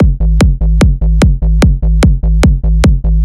hardstyle kick gated fat - D#m.wav
Original creative-commons licensed sounds for DJ's and music producers, recorded with high quality studio microphones.
hardstyle_kick_gated_fat_-_d_sharp_m_zl5.ogg